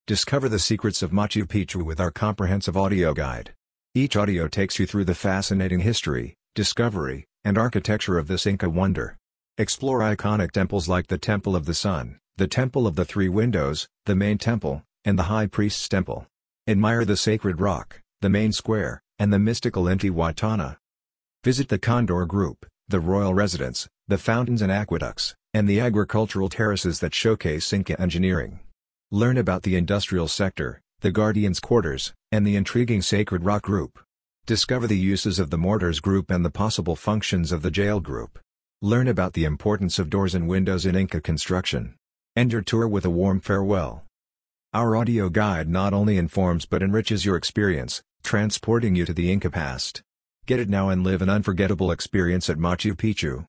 The Machu Picchu Audio Guide provides an immersive experience with 26 carefully narrated tracks.